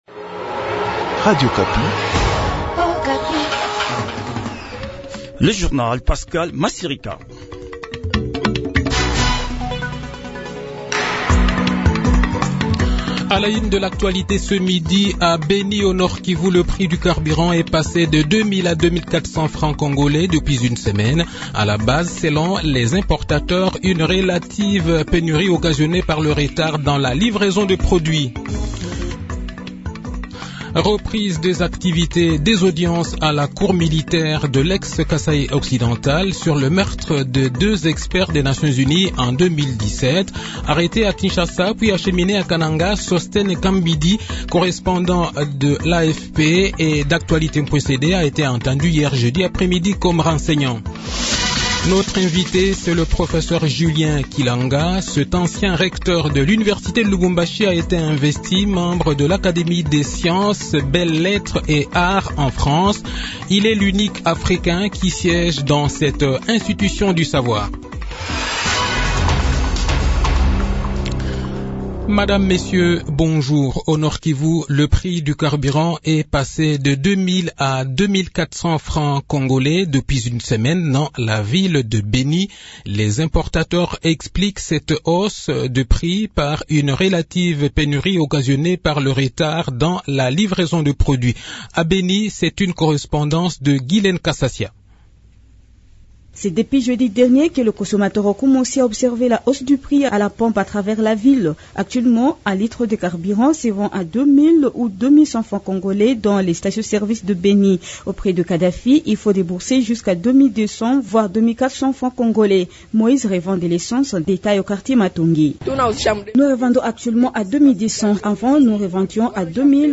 Journal Midi
Le journal de 12 h, 1er octobre 2021